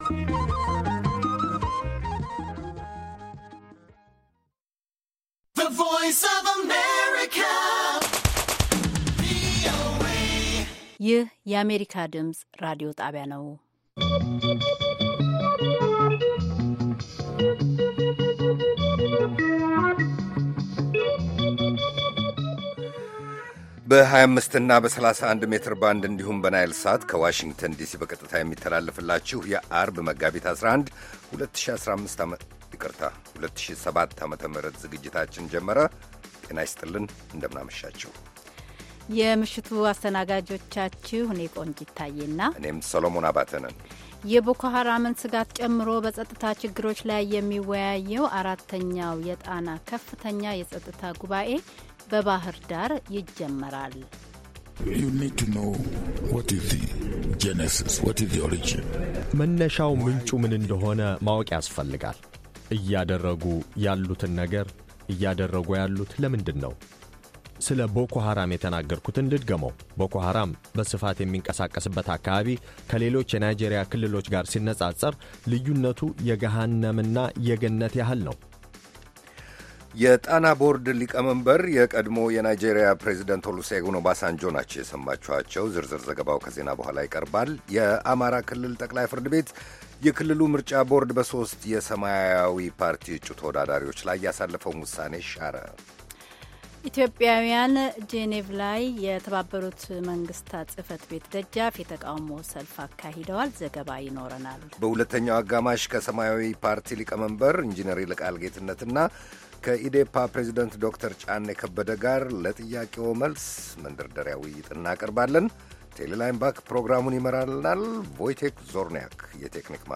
ቪኦኤ በየዕለቱ ከምሽቱ 3 ሰዓት በኢትዮጵያ ኣቆጣጠር ጀምሮ በአማርኛ፣ በአጭር ሞገድ 22፣ 25 እና 31 ሜትር ባንድ የ60 ደቂቃ ሥርጭቱ ዜና፣ አበይት ዜናዎች ትንታኔና ሌሎችም ወቅታዊ መረጃዎችን የያዙ ፕሮግራሞች ያስተላልፋል። ዐርብ፡- እሰጥ አገባ፣ አፍሪካ በጋዜጦች፡ አጥቢያ ኮከብ (የማኅበረሰብ ጀግኖች)